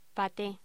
Locución: Paté
voz
Sonidos: Alimentación